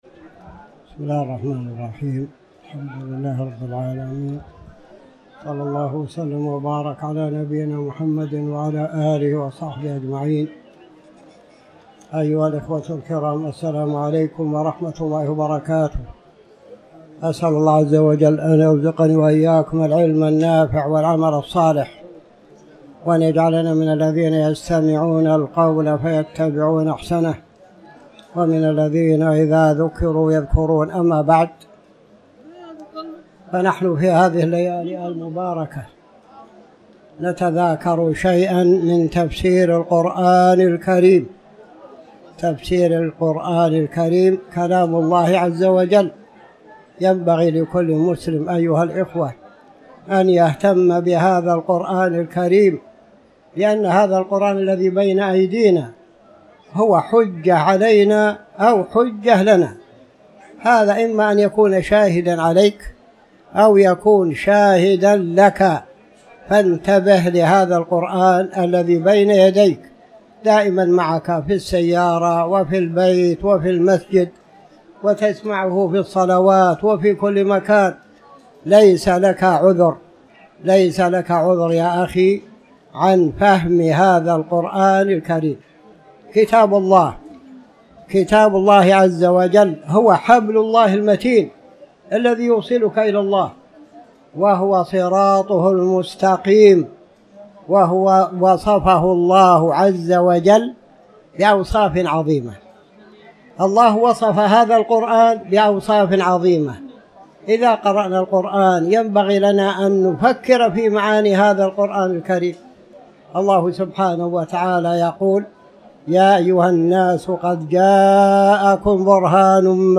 تاريخ النشر ٢٠ رمضان ١٤٤٠ هـ المكان: المسجد الحرام الشيخ